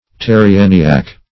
Terrienniak \Ter`ri*en"ni*ak\, n.